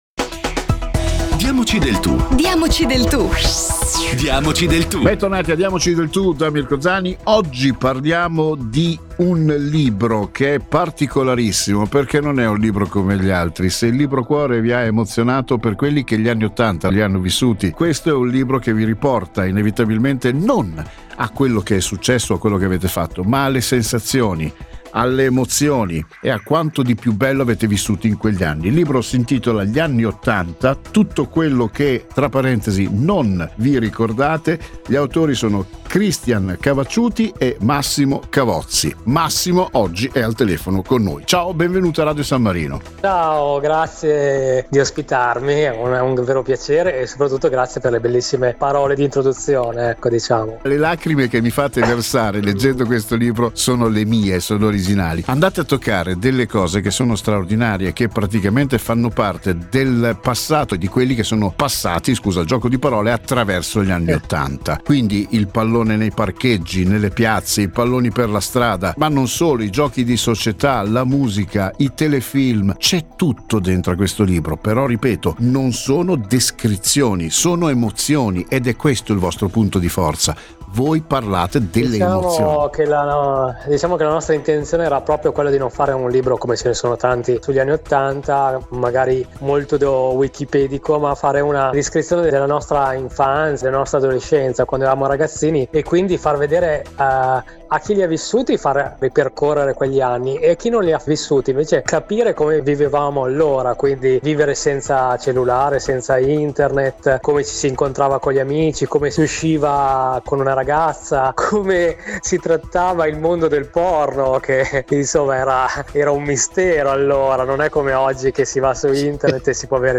Siamo stati ospiti a Radio San Marino per parlare del nostro libro.